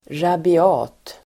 Ladda ner uttalet
Uttal: [rabi'a:t]